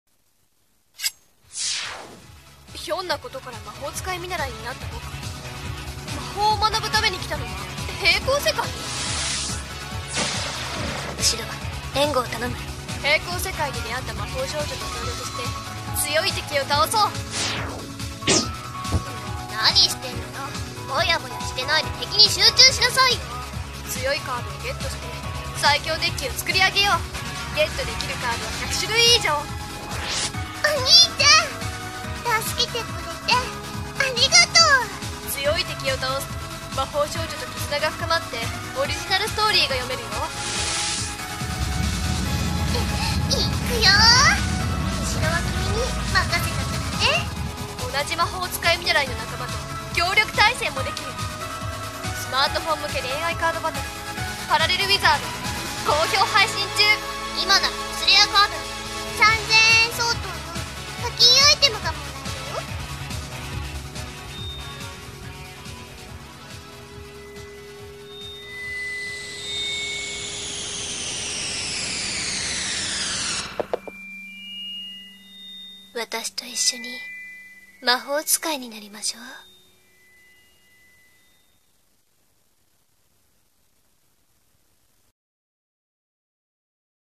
【声劇台本】偽恋愛カードゲームアプリCM